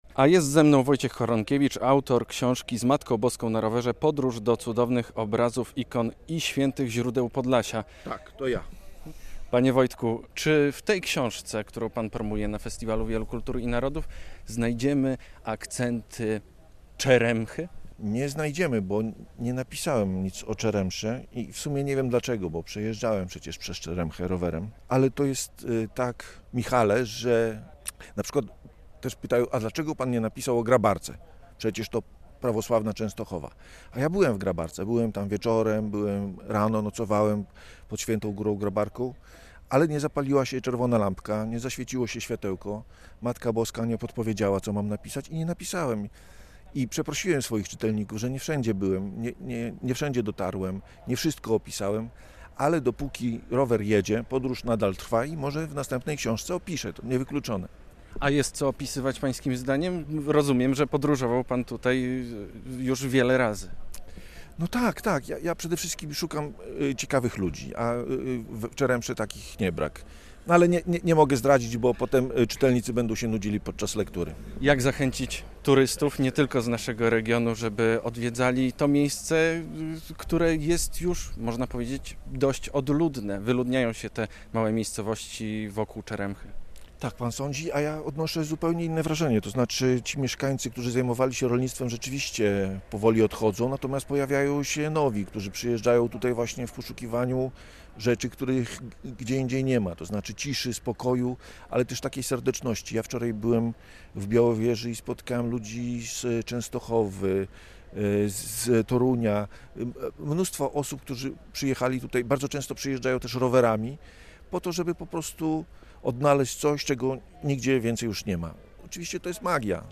Plenerowe studio Polskiego Radia Białystok stanęło na terenie Festiwalu Wielu Kultur i Narodów.